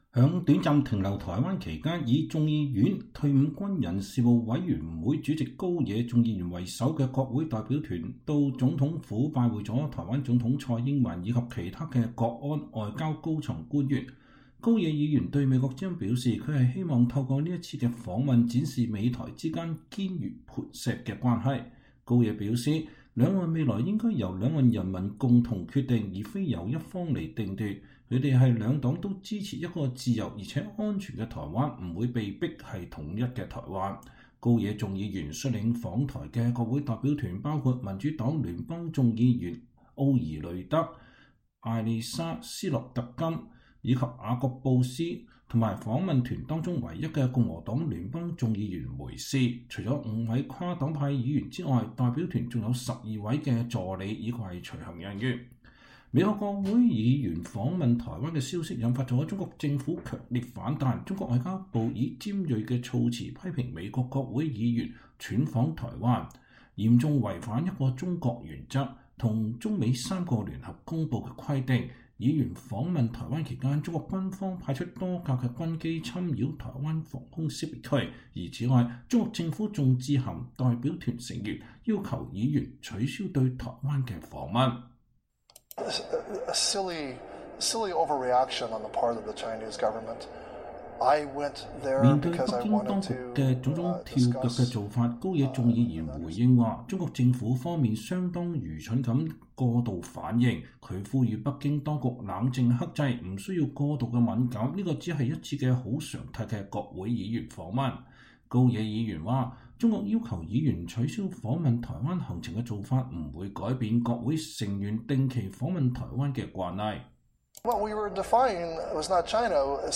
美國國會民主黨聯邦眾議員馬克·高野（Rep. Mark Takano, D-CA）2021年12月1日接受美國之音專訪。